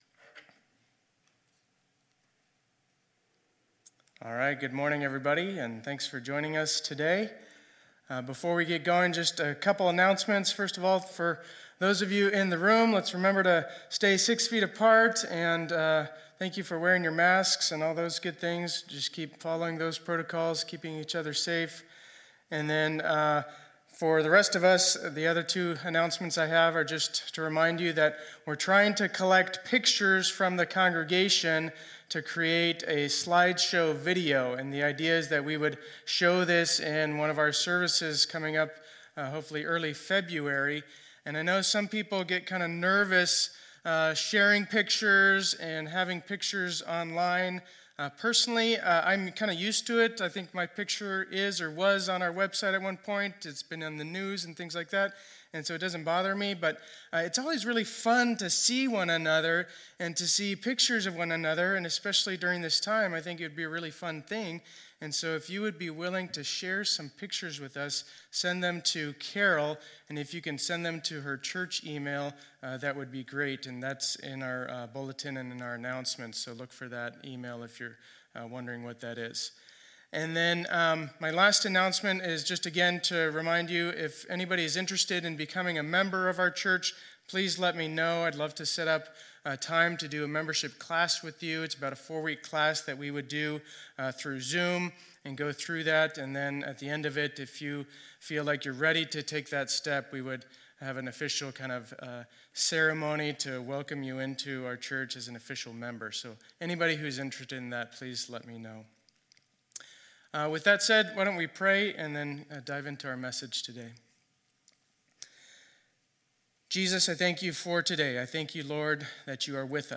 2021-01-24 Sunday Service